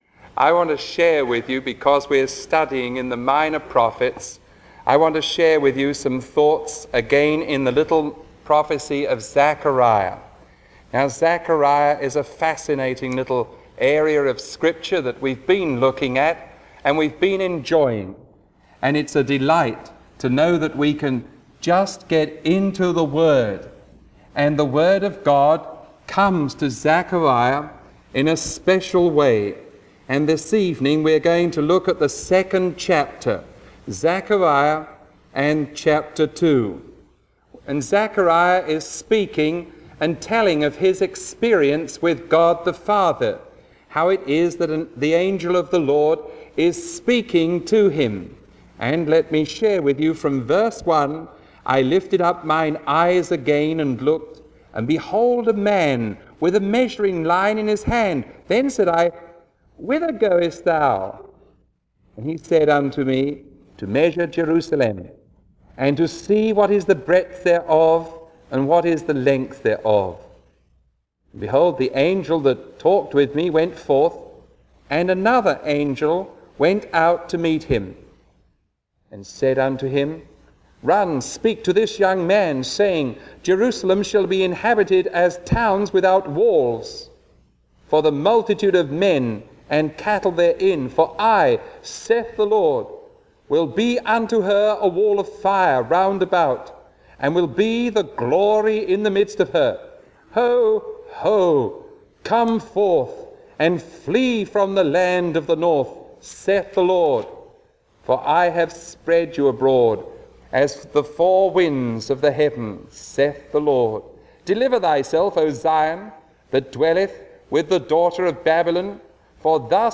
Sermon 0019B recorded on January 28